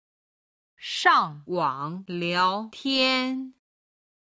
今日の振り返り！中国語発声
上网聊天　(shàng wǎng liáo tiān)　チャット